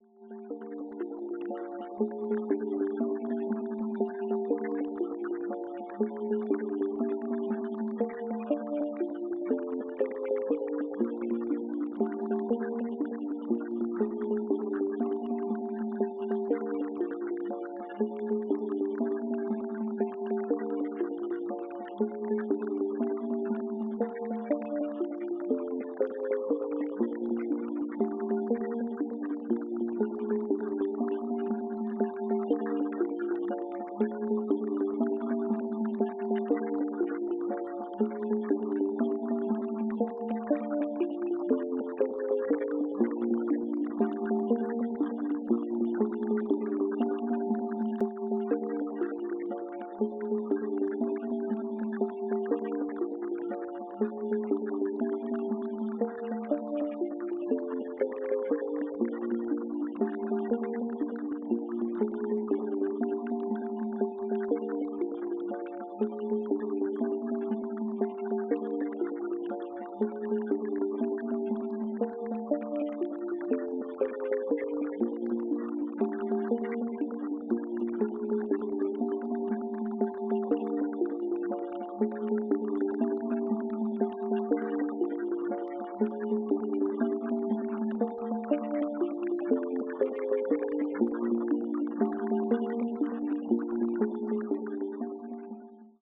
Type BGM
Speed 50%